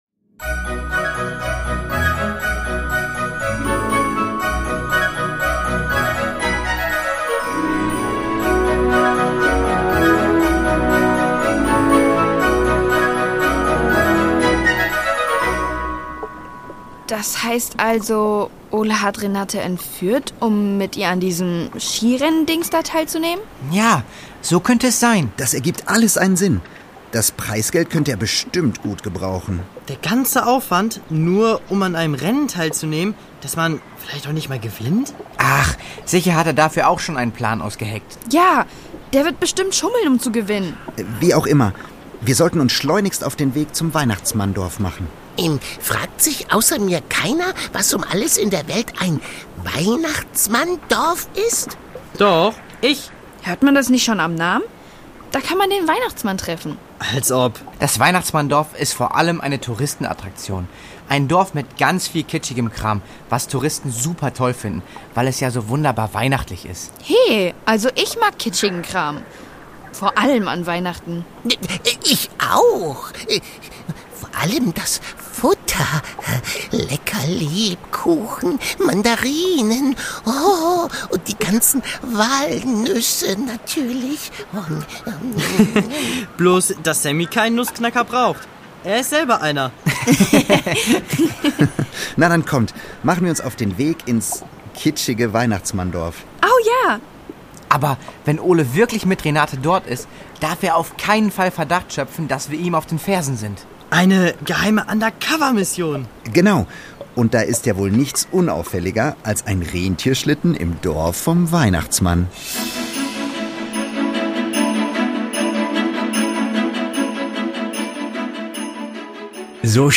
Die Doppeldecker Crew | Hörspiel für Kinder (Hörbuch)